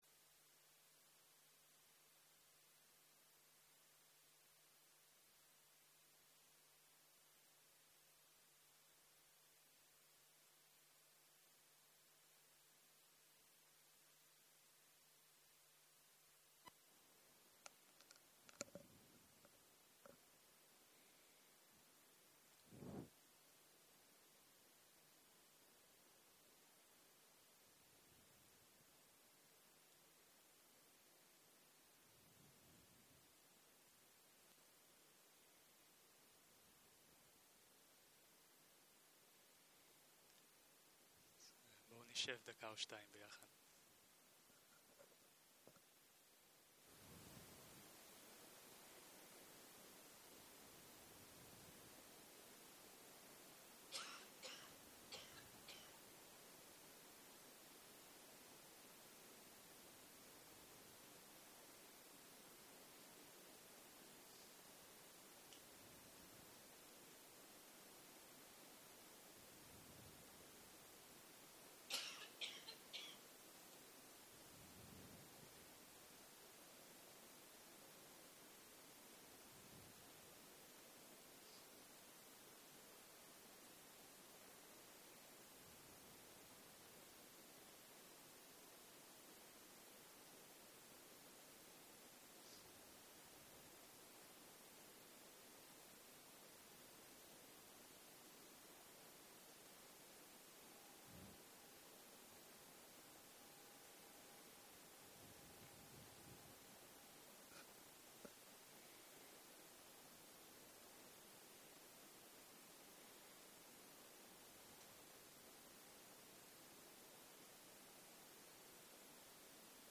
יום 9 - בוקר - הנחיות מדיטציה - הנחיות יום מלא אחרון - הקלטה 22 0:00 0:00 סוג ההקלטה: Dharma type: Guided meditation שפת ההקלטה: Dharma talk language: Hebrew